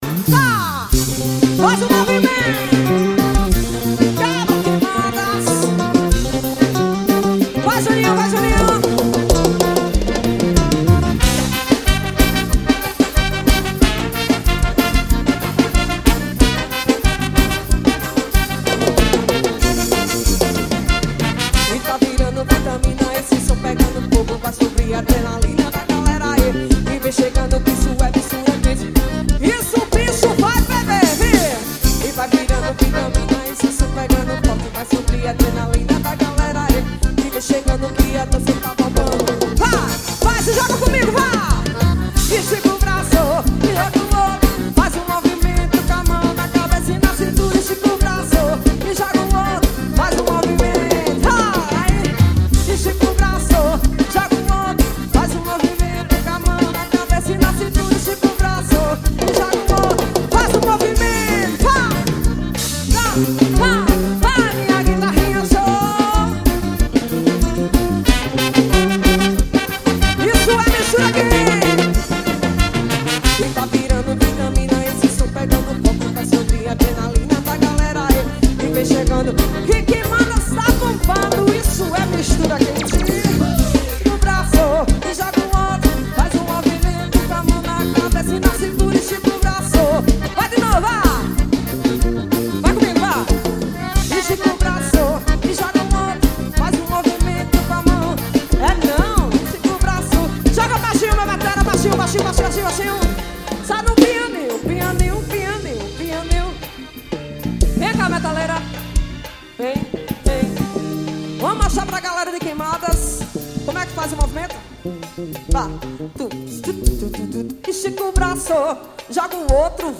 ao vivo (QUEIMADAS PB)